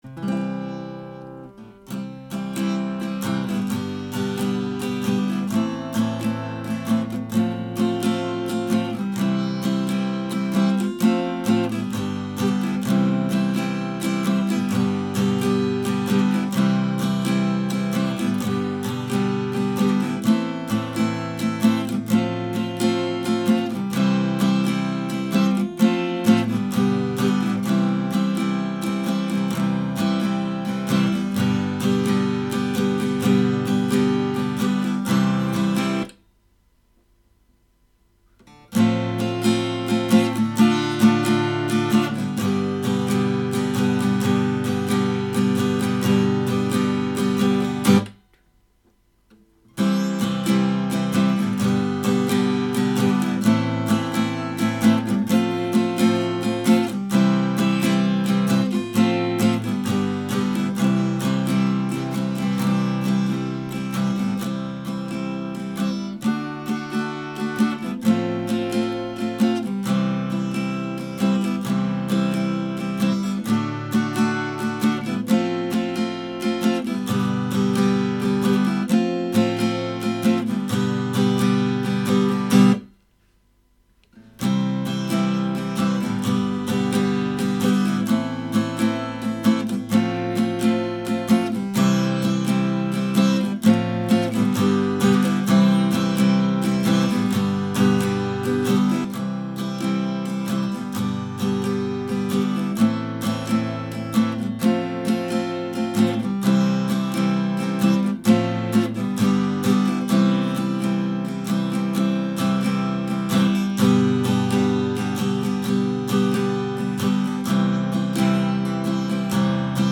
Übungsaufnahmen - Der Computer Nr. 3
Der_Computer_Nr_3__5_Playback.mp3